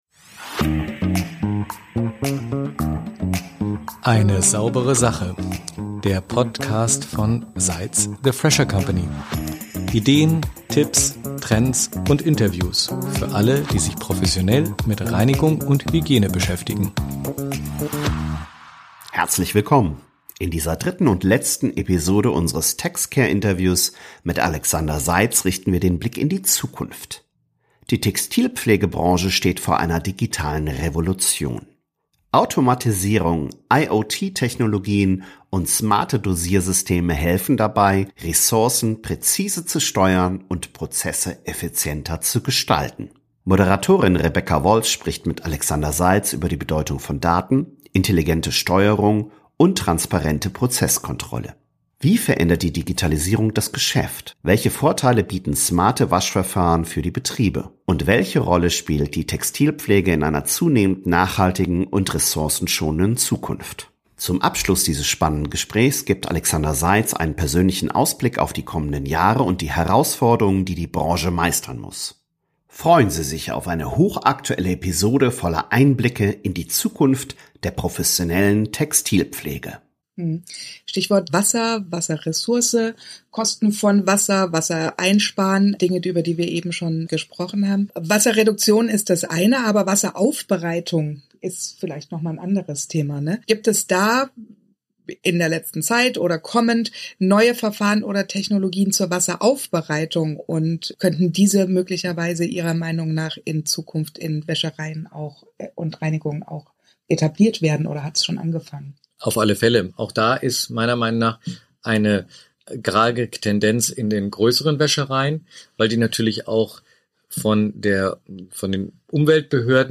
Digitalisierung, Automatisierung und die Zukunft der Textilpflege – Ein Texcare-Interview“ ~ Eine saubere Sache Podcast